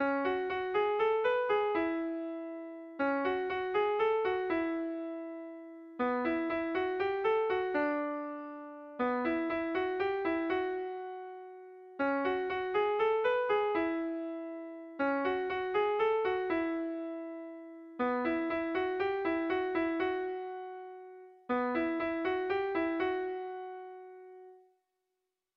Kontakizunezkoa
Zortziko ertaina (hg) / Lau puntuko ertaina (ip)
AB1AB2